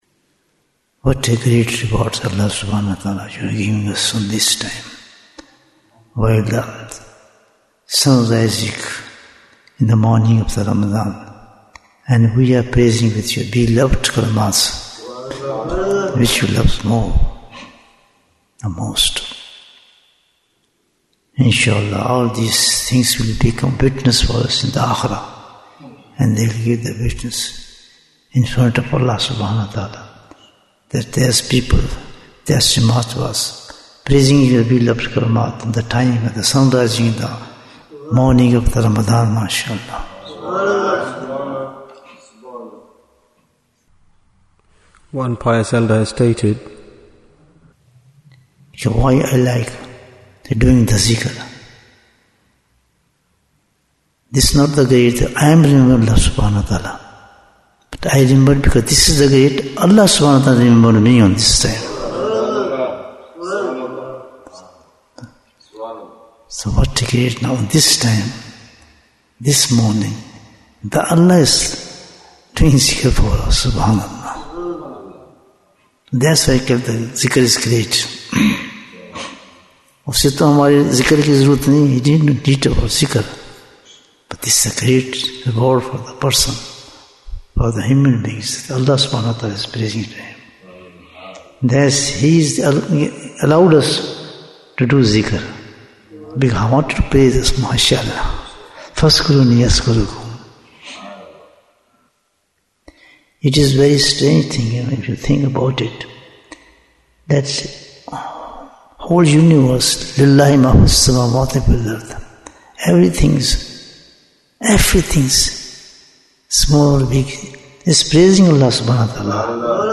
Bayan, 6 minutes